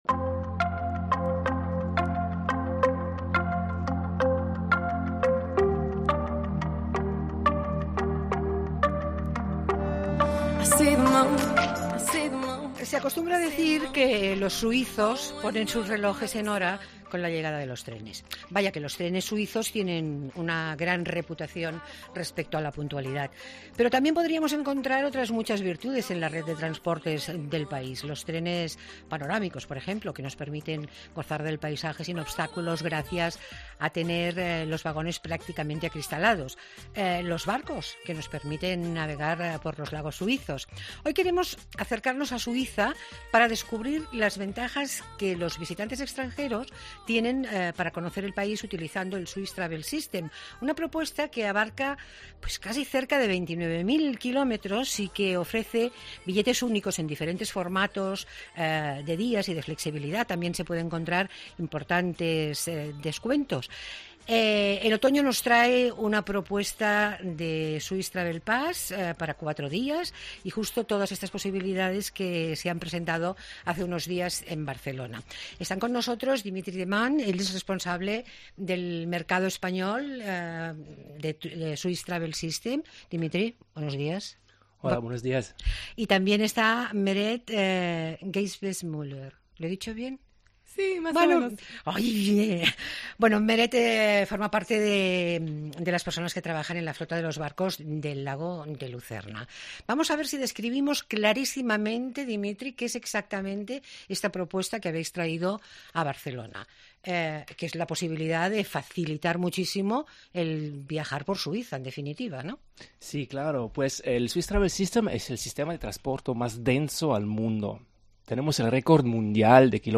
Todos los domingos hacemos una hora de radio pensada para aquellos que les gusta pasarlo bien en su tiempo de ocio ¿donde?